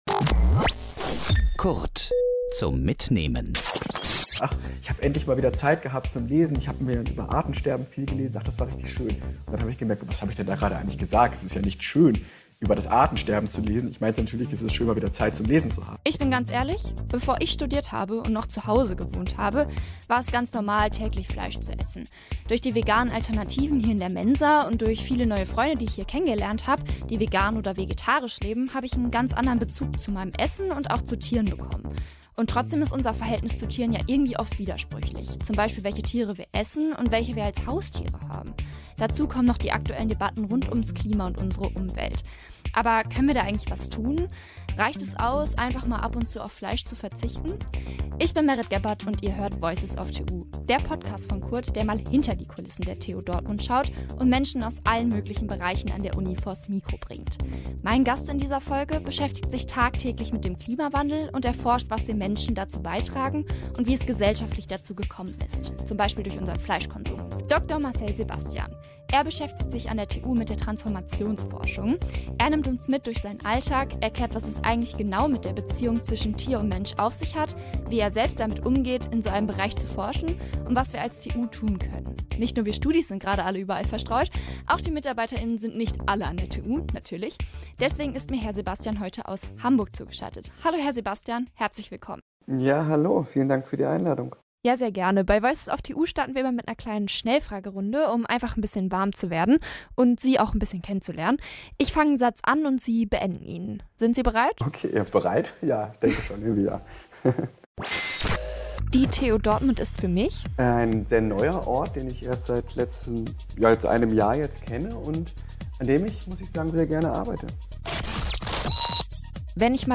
Im Gespräch erzählt er auch, wie er zu seinem Studienfach kam und welche Formen der Tierhaltung es gibt.